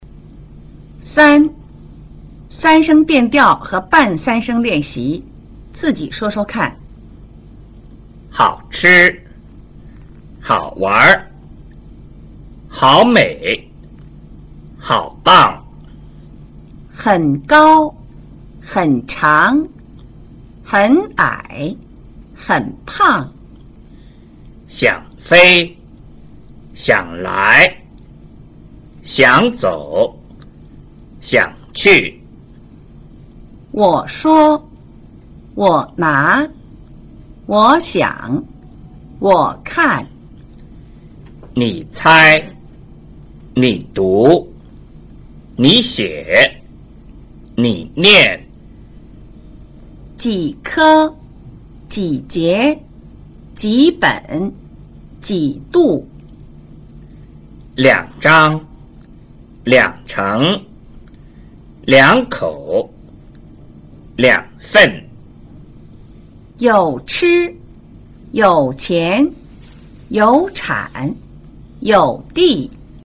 在語流中,第三聲詞往往讀成半三聲,也就是只讀音節聲調的降調部份。
3. 三聲變調和半三聲練習, 自己說說看: